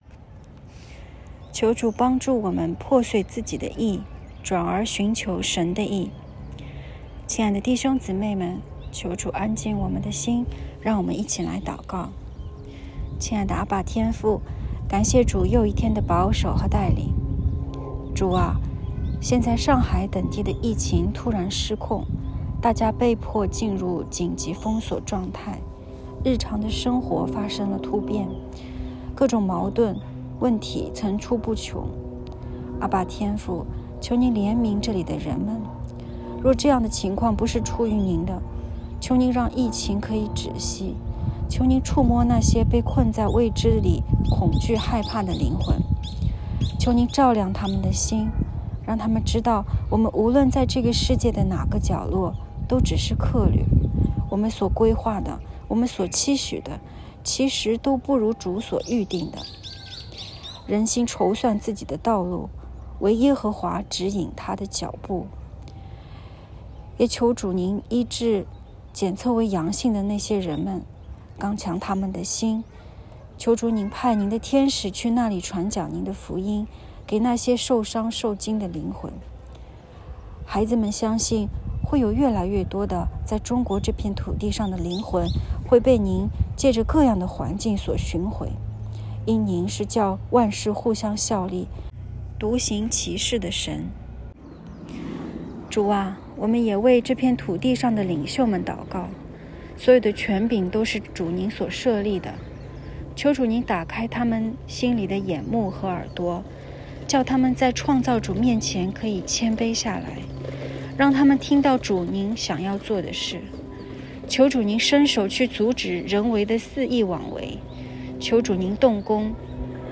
✨晚祷时间✨4月8日（周五）